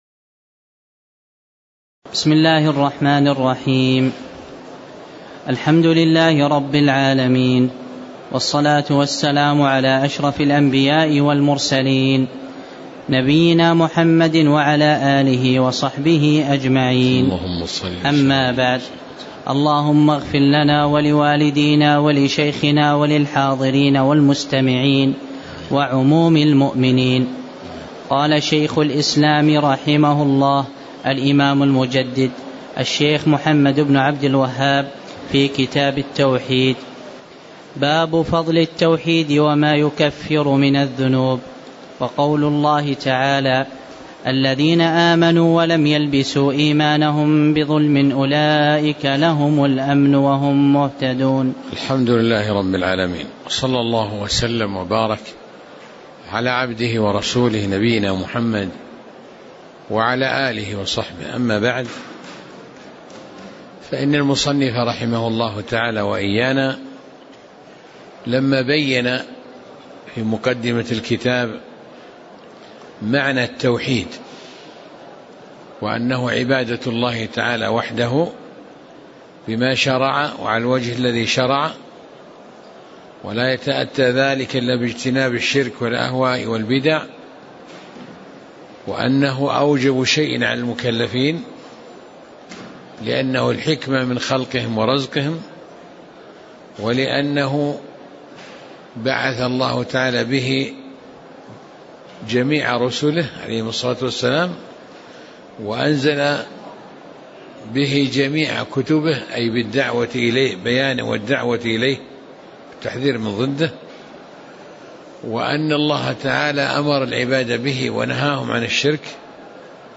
تاريخ النشر ٢ رجب ١٤٣٨ هـ المكان: المسجد النبوي الشيخ